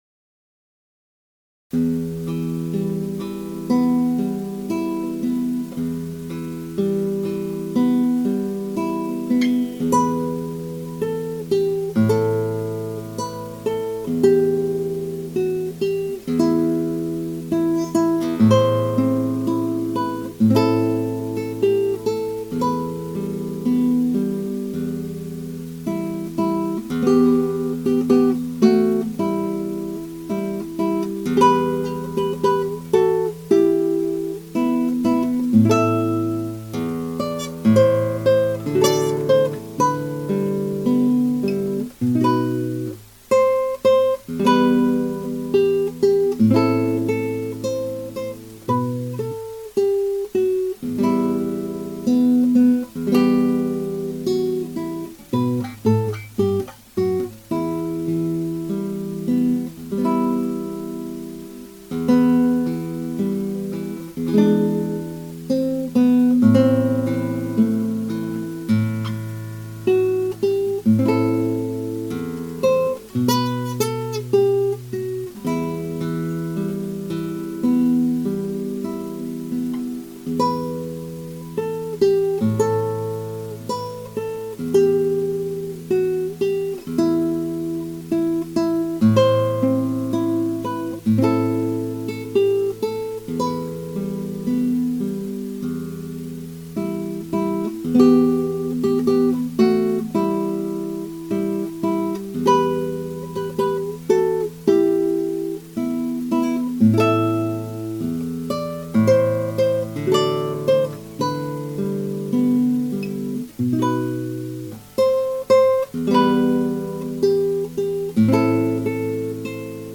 [Guitar amatuer play]